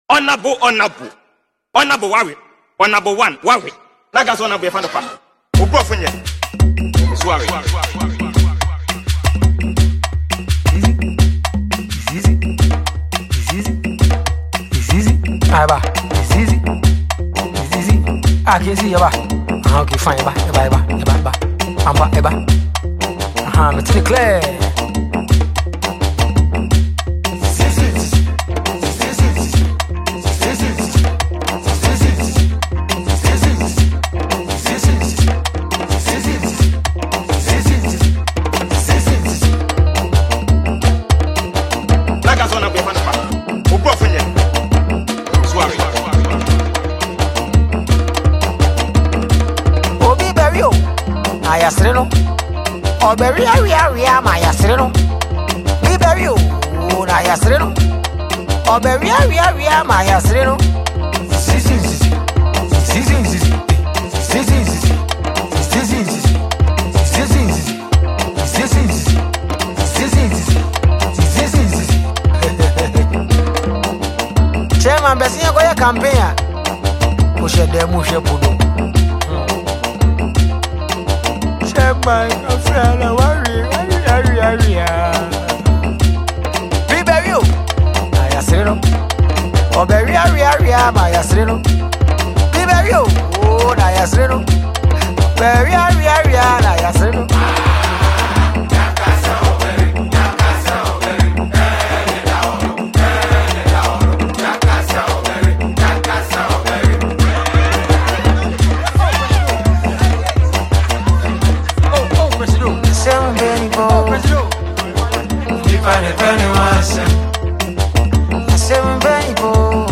smashing feet dazzling tune